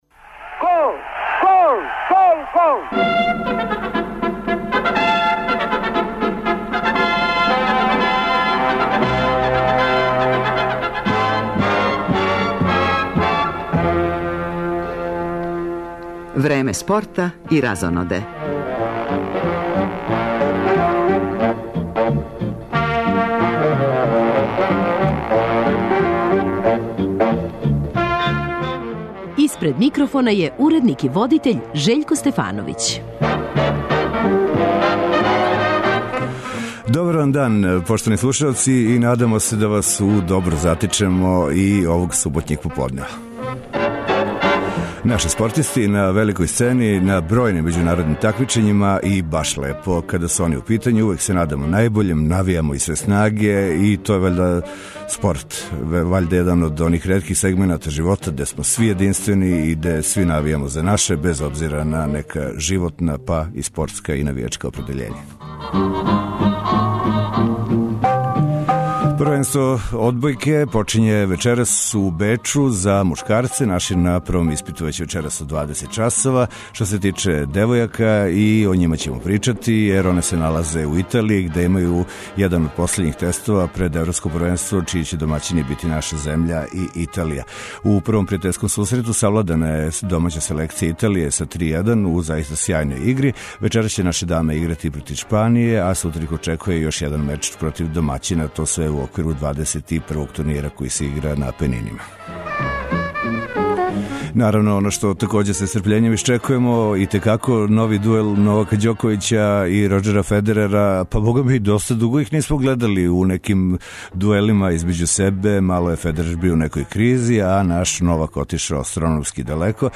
Током емисије пратићемо комбиноване преносе утакмица Суперлиге, као и два прволигашка меча: Бежанија - Инђија и Синђелић-Раднички.